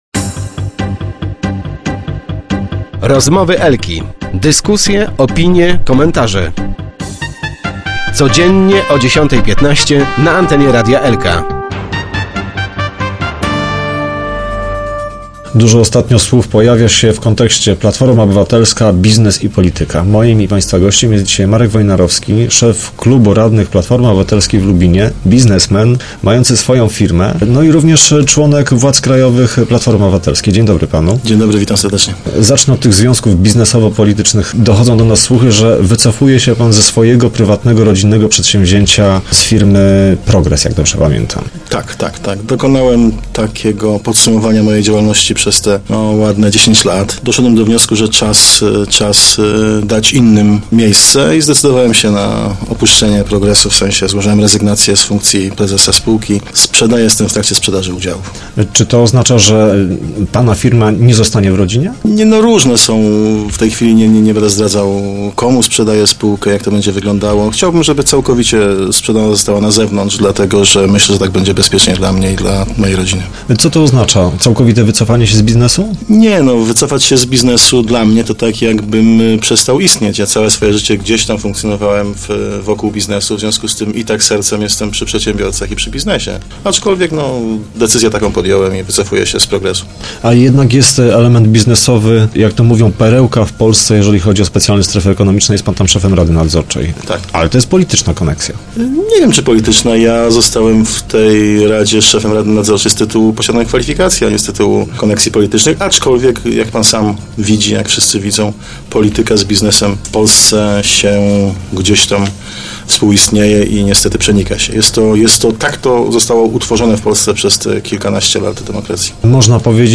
wojnarowski_marek.jpgLubin. Rezygnuje z prowadzenia własnej firmy, ale nie zamierza zajmować się tylko polityką. Marek Wojnarowski/na zdj/, radny i szef klubu radnych Platformy Obywatelskiej w powiecie lubińskim, członek władz krajowych PO, lokalny biznesmen, który osiągnął finansowy sukces, w studiu Radia Elka w Lubinie przyznaje, że polityka z biznesem w Polsce współistnieje i przenika się wzajemnie.